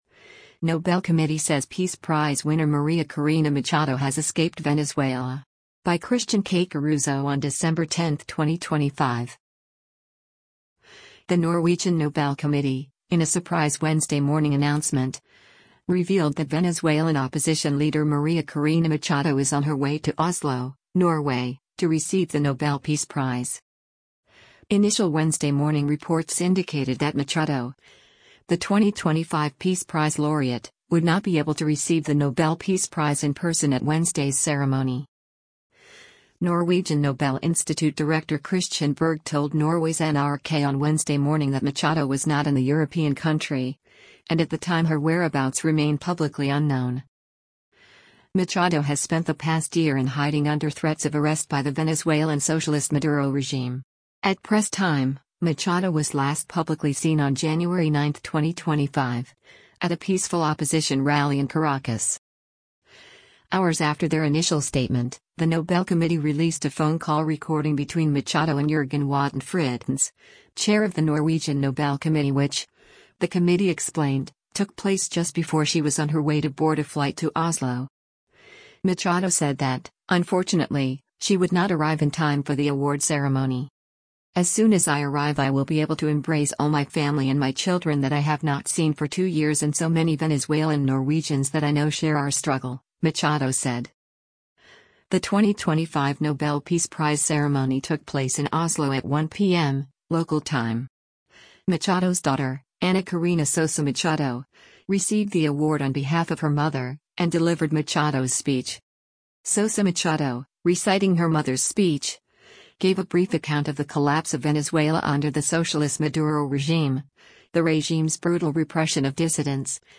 Hours after their initial statement, the Nobel Committee released a phone call recording between Machado and Jørgen Watne Frydnes, chair of the Norwegian Nobel Committee which, the Committee explained, took place “just before” she was on her way to board a flight to Oslo. Machado said that, unfortunately, she would not arrive in time for the award ceremony.